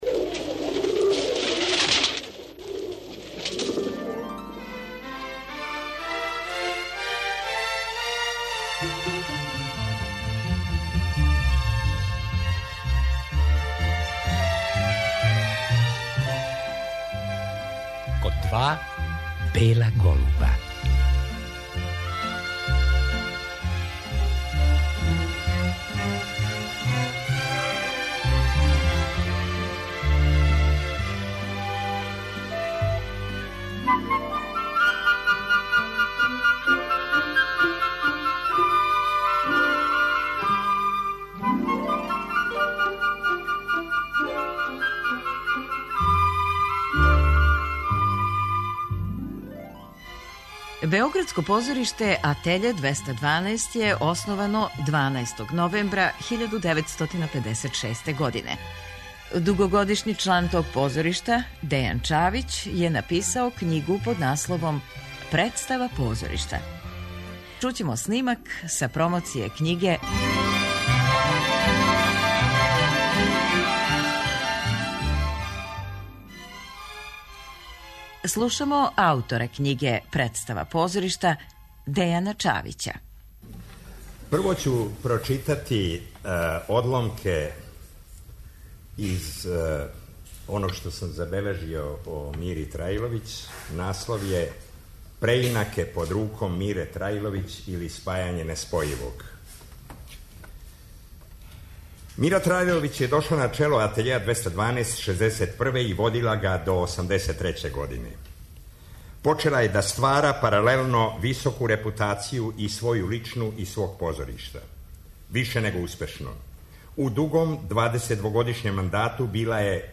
Чућемо снимак са промоције књиге на којој су говорили глумица Ксенија Јовановић